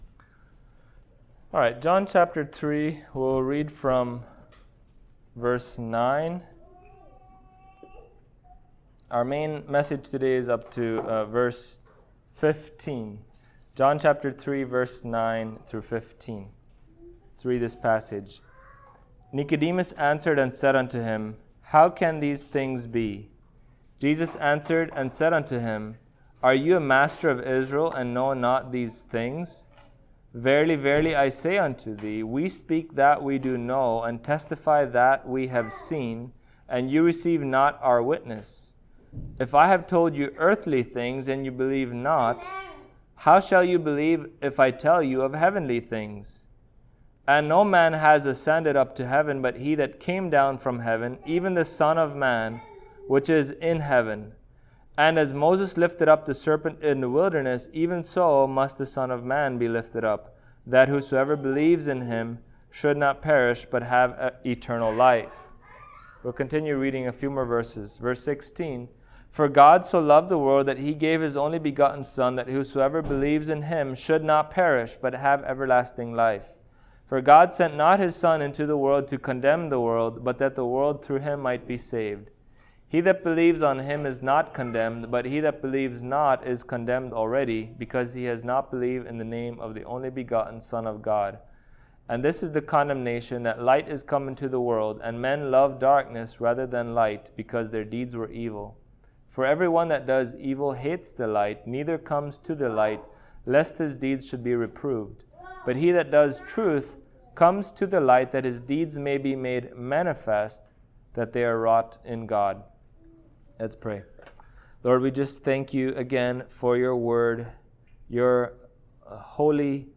Passage: John 3:9-15 Service Type: Sunday Morning Topics